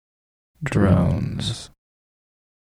"drones" 2 sec. stereo 55k
drones.mp3